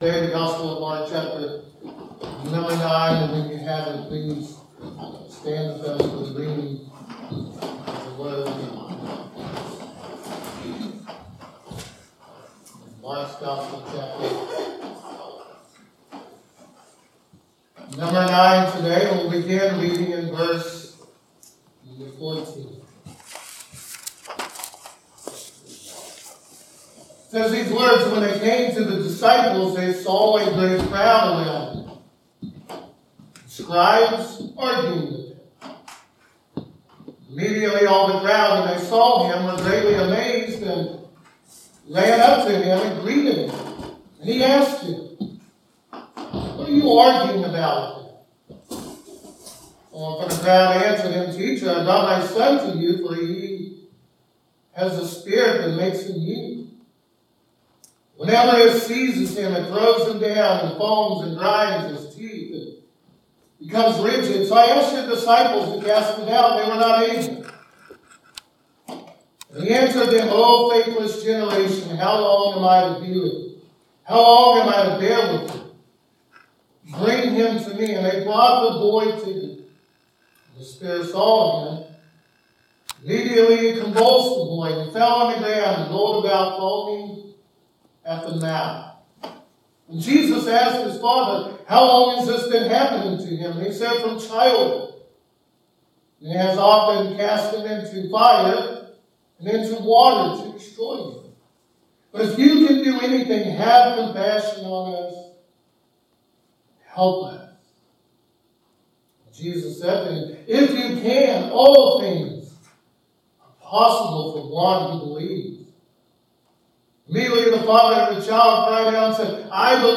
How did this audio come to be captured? Mark 9:14-29 Service Type: Sunday Morning True faith is depending on Christ even in the most difficult times of life.